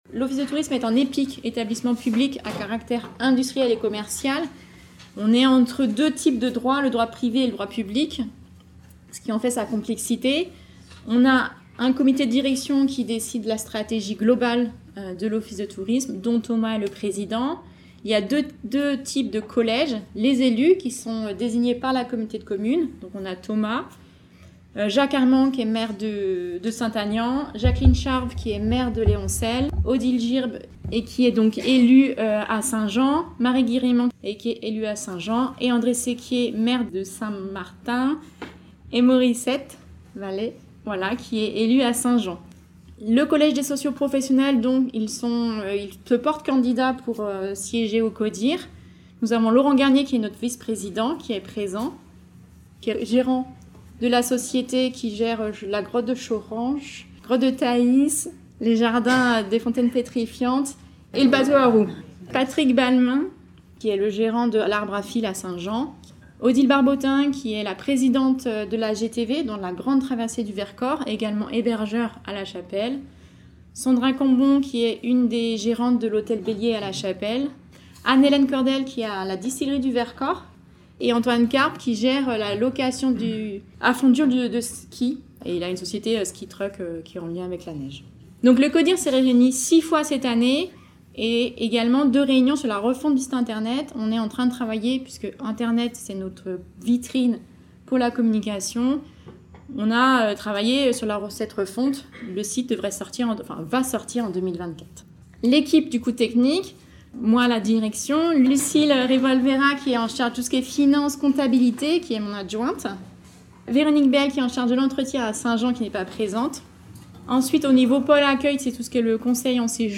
Captation réalisée au centre d’art “L’Artsolite” qui accueillait à cet effet les 2èmes rencontre du tourisme, événement annuel à destination des acteurs touristiques du territoire.